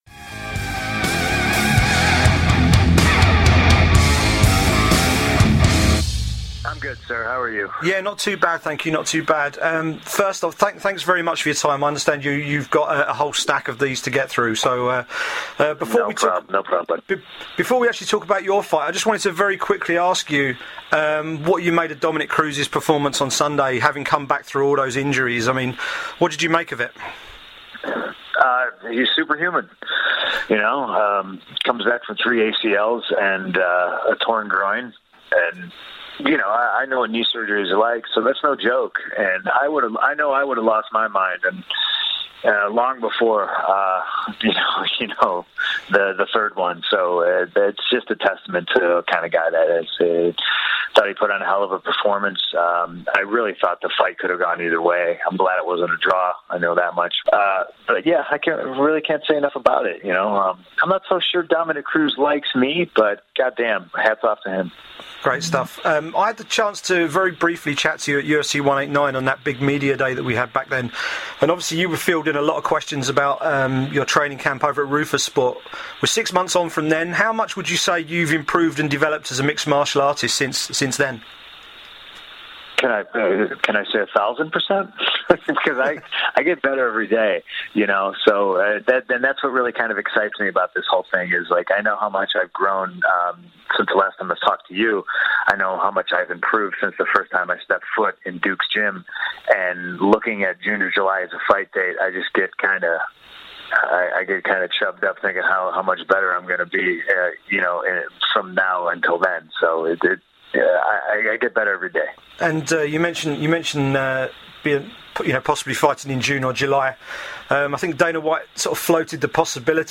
Former WWE superstar CM Punk chats to MMA journalist